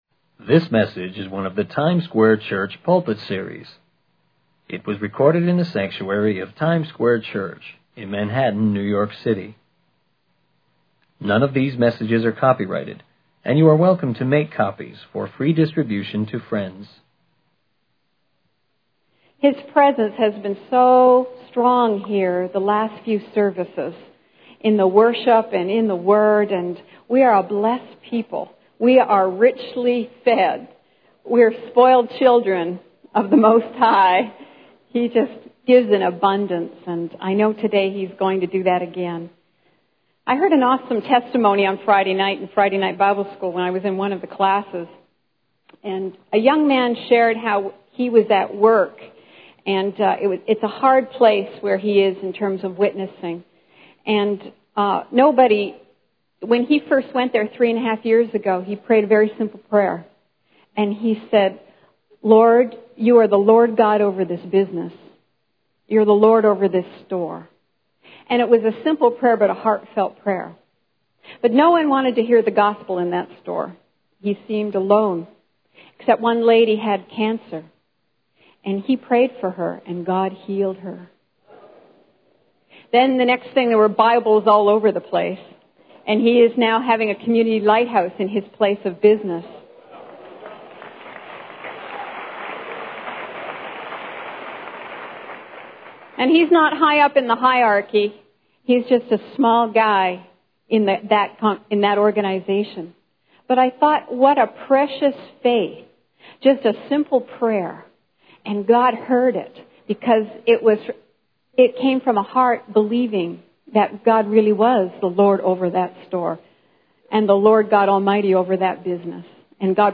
Full Transcript This message is one of the Times Square Church Pulpit Series. It was recorded in the sanctuary of Times Square Church in Manhattan, New York City.